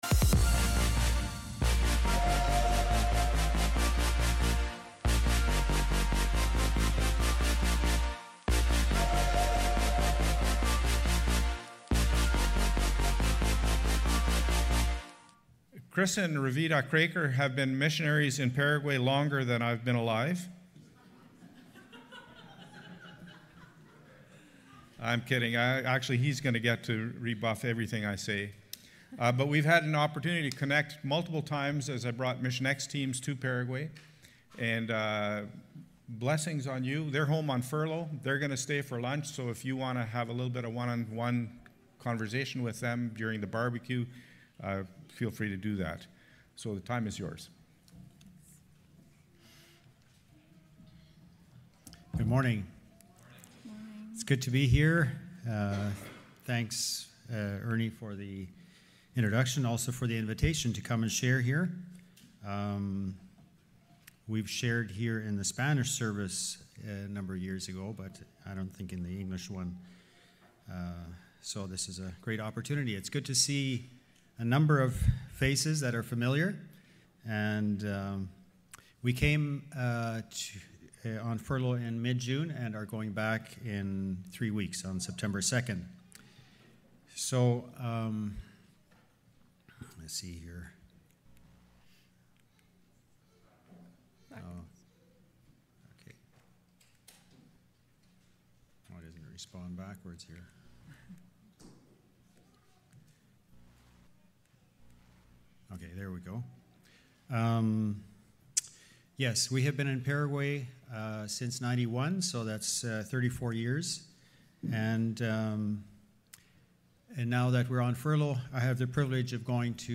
Past Message